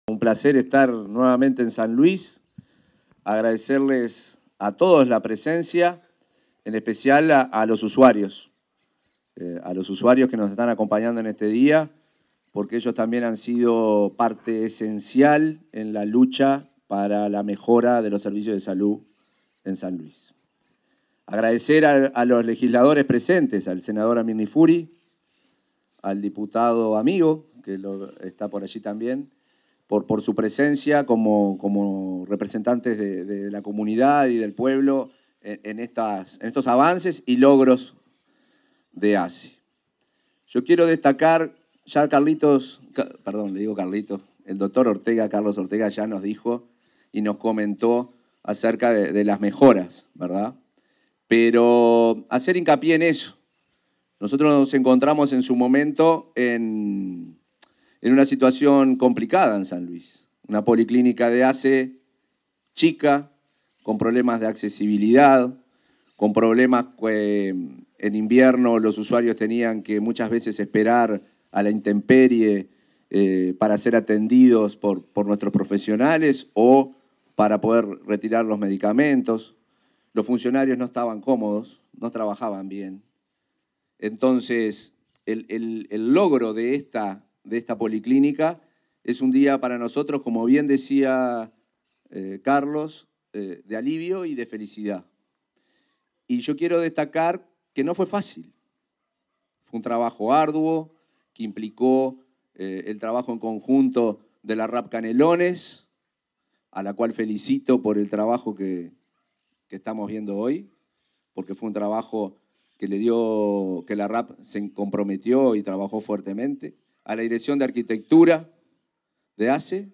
Palabras del presidente de ASSE, Marcelo Sosa
En el marco de la ceremonia de inauguración de la policlínica de San Luis, se expresó el presidente de la Administración de los Servicios de Salud del